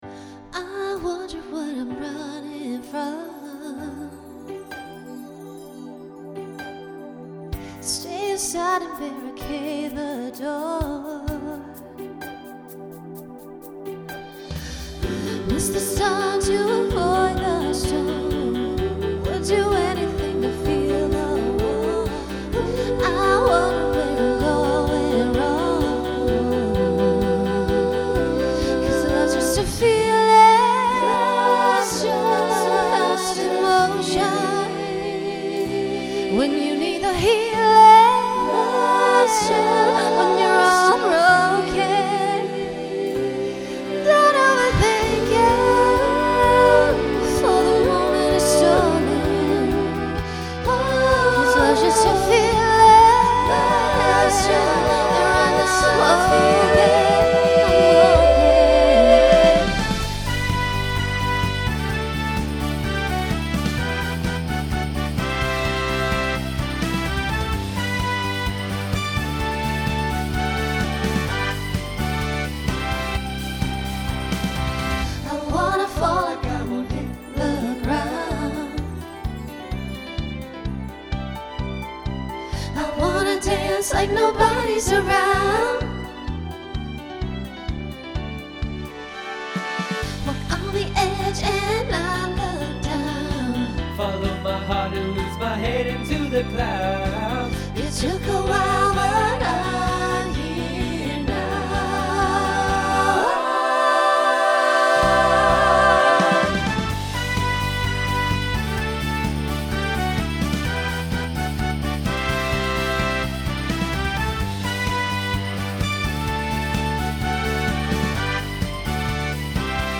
Genre Pop/Dance
Solo Feature Voicing SATB